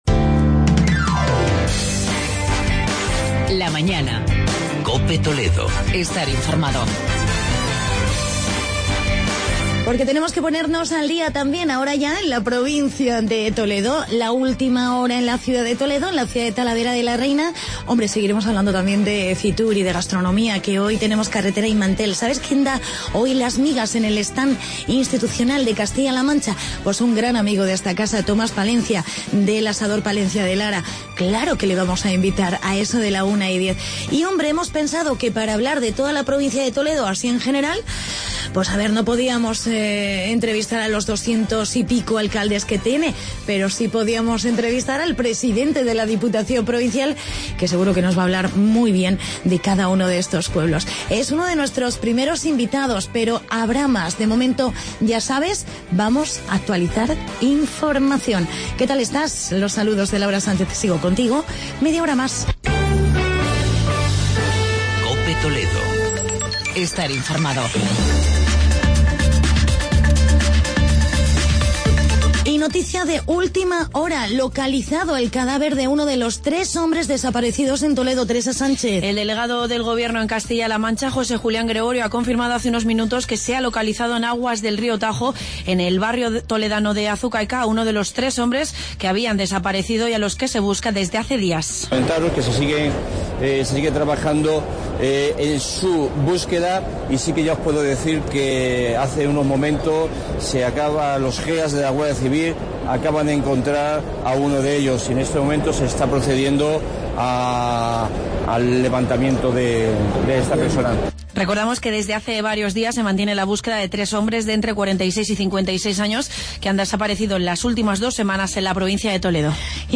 Entrevista con el presidente de la Diputación de Toledo, Álvaro Gutierrez